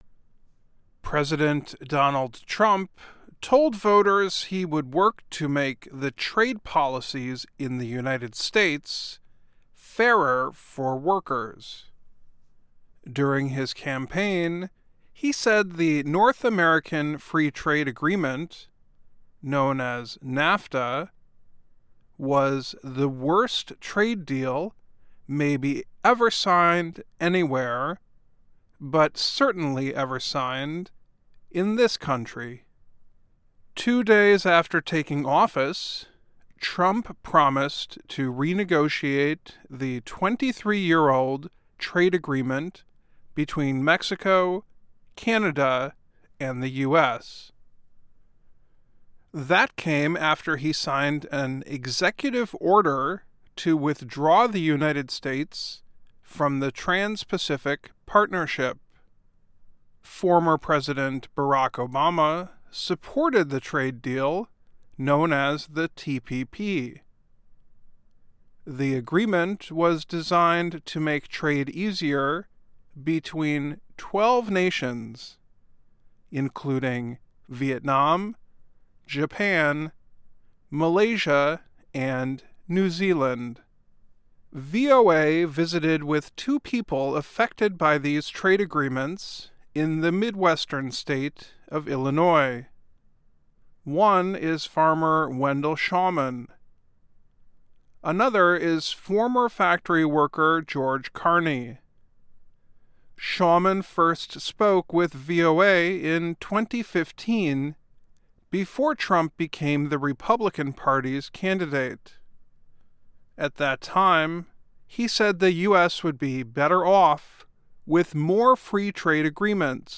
A farmer and a factory worker in the Midwestern state of Illinois discuss President Donald Trump’s trade policies.